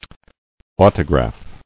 [au·to·graph]